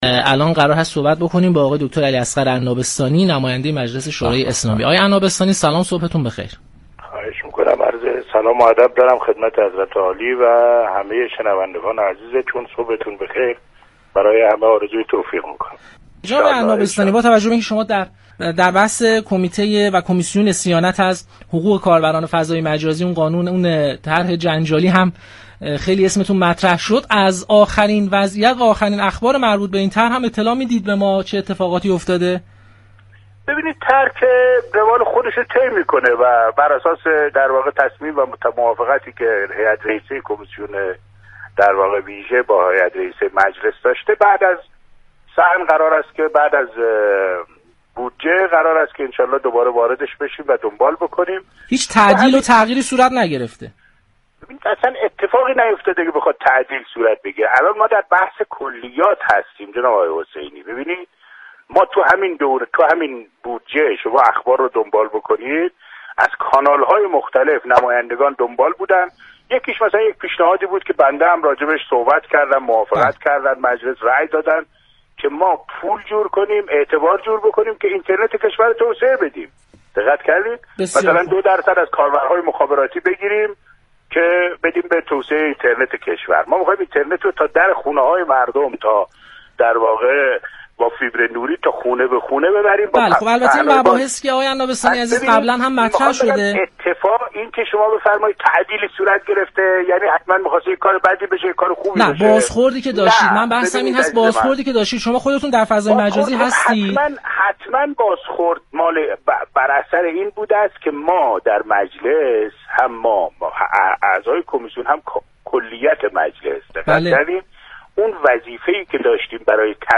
نماینده مردم سبزوار در مجلس یازدهم در پاسخ به این پرسش كه آیا تغییر و تعدیلی در اجرای طرح صیانت از حقوق كاربران در فضای مجازی صورت گرفته است یا خیر؟